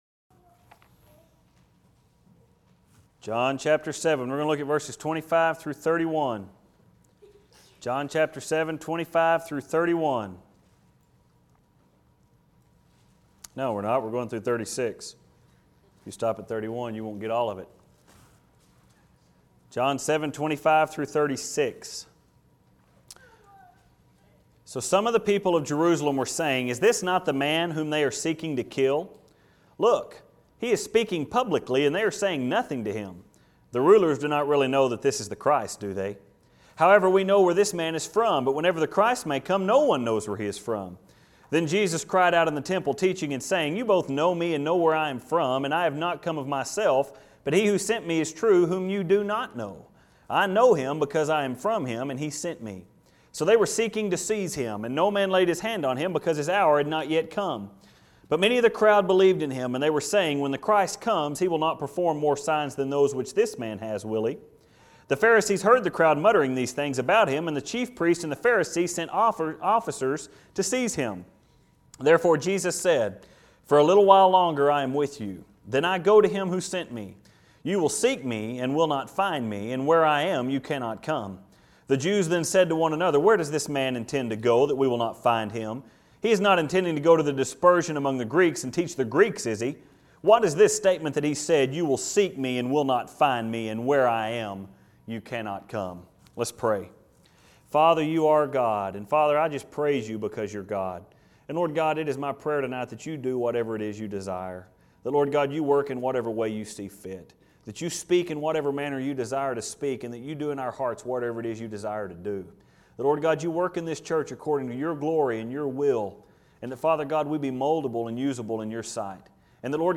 We will work our way through the text, But our main sermon is found in verses 33-34.